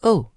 描述：女人ooohing。 使用我自己的声音创建，使用Blue Snowball麦克风和Audacity。
标签： 声音 惊讶 声乐 gamesound SFX 女人
声道立体声